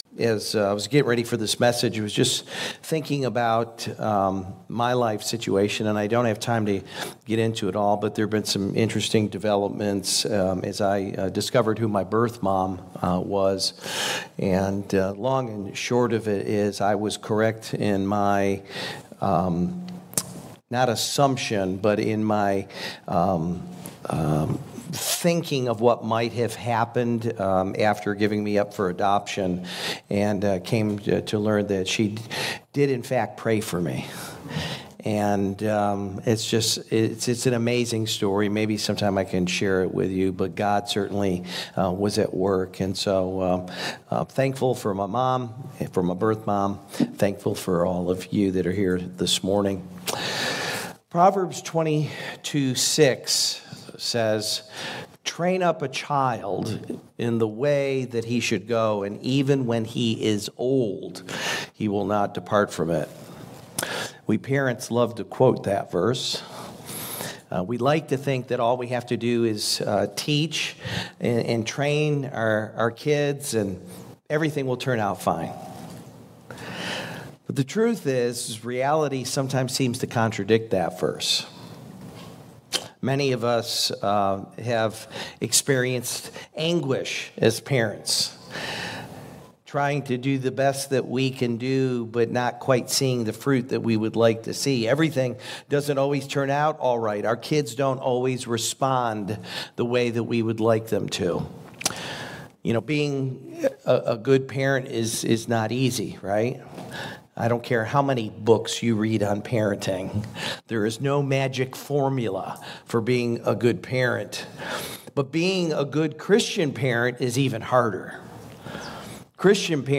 This feed features the weekly sermons and discussion at NewLife Church in Canal Winchester. Subscribe as we dive in to study the Bible together!